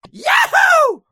Cheer2.wav